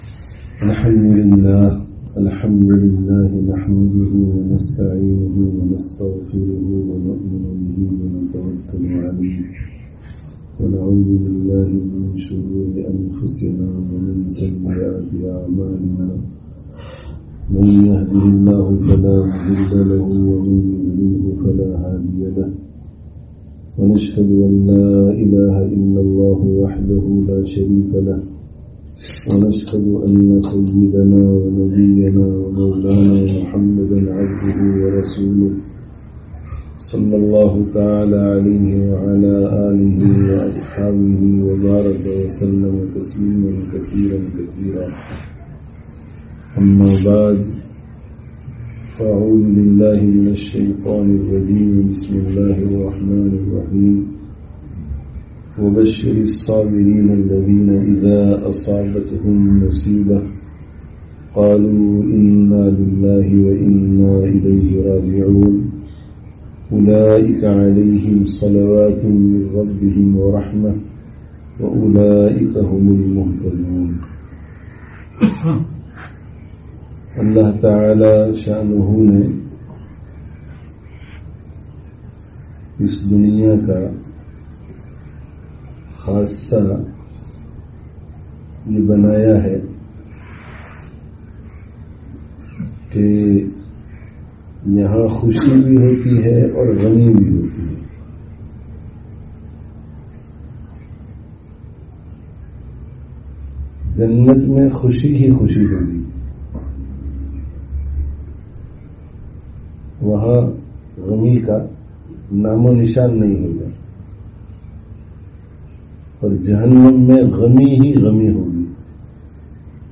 Hamārī har Hālat Allāh kī Taraf se hai (Upton Lane Masjid, London 22/07/18)